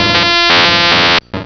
pokeemerald / sound / direct_sound_samples / cries / electabuzz.aif